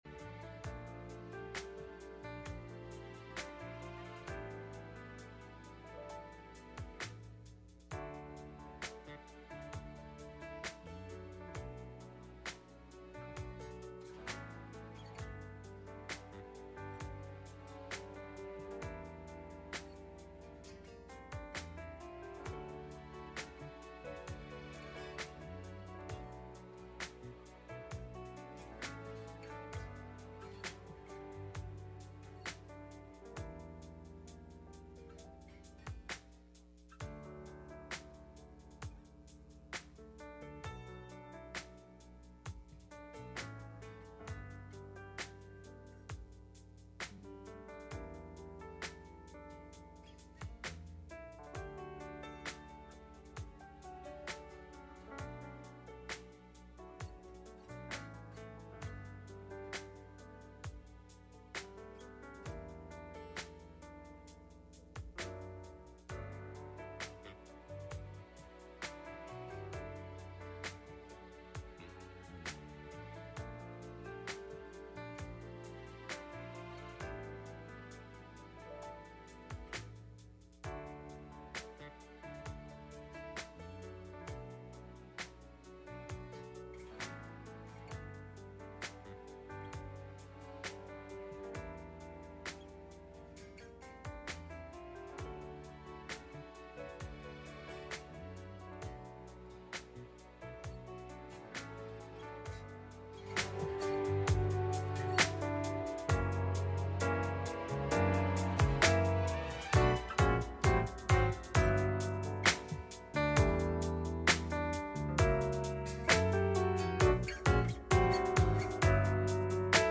Message Service Type: Midweek Meeting https